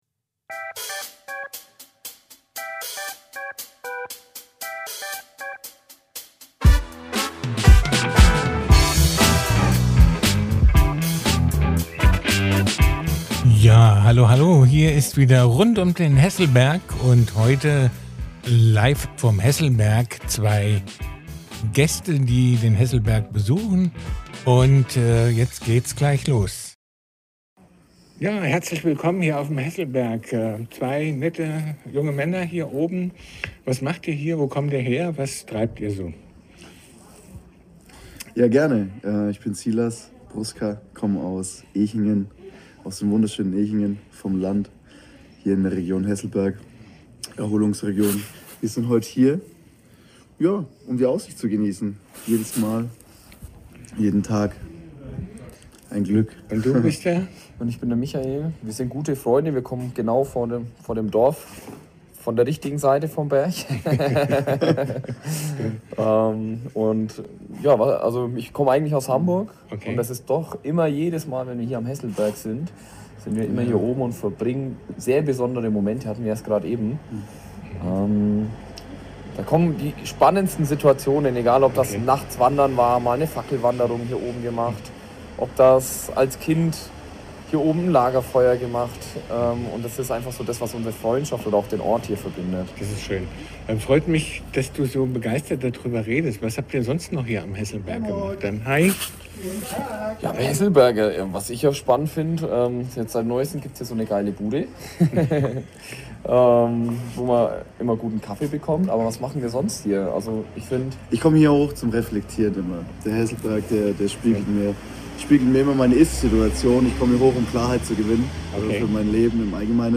Zwei Freunde teilen besondere Momente und Erinnerungen auf dem Hesselberg.